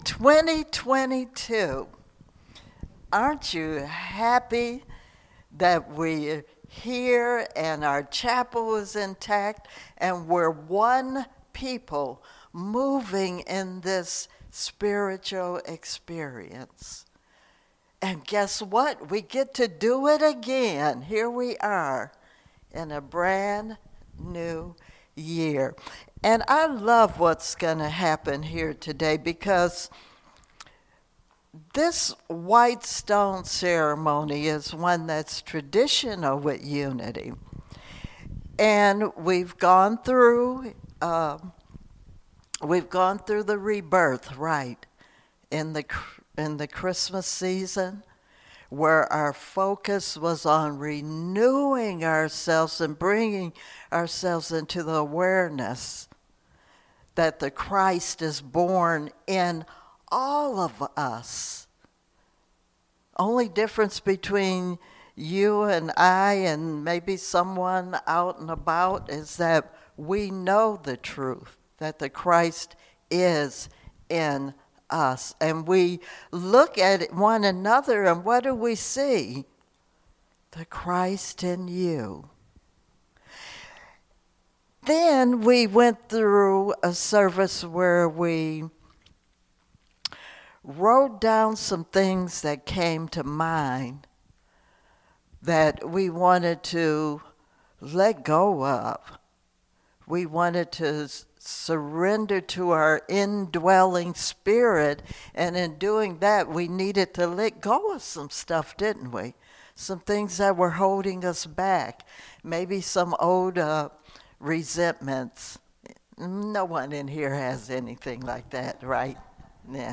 Sermons 2022